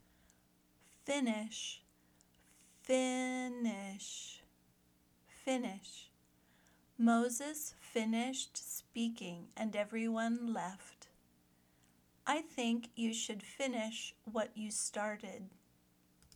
/ˈfɪn ɪʃ/ (verb)